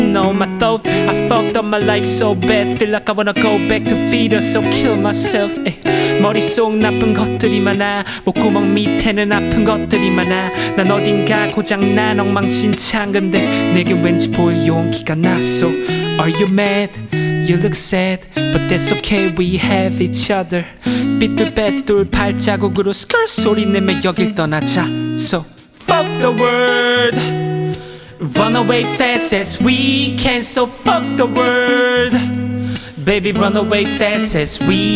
Korean Hip-Hop
Жанр: Хип-Хоп / Рэп / Поп музыка